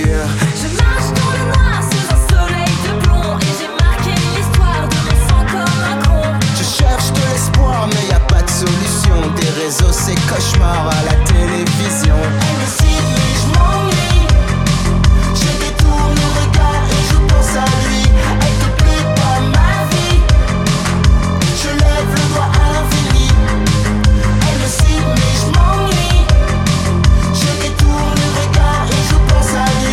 Жанр: Рок